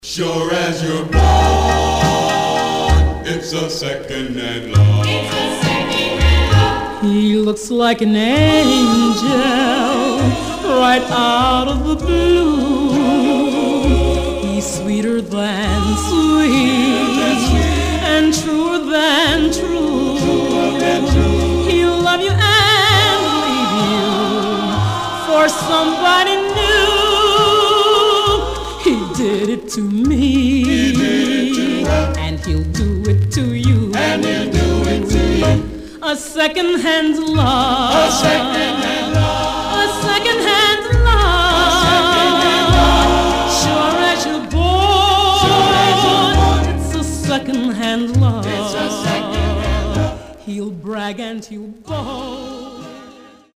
Stereo/mono Mono
45s